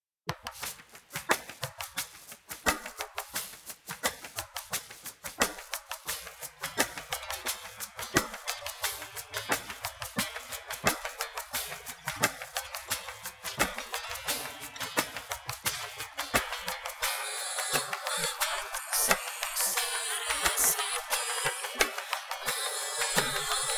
nontonal part (original - tonal)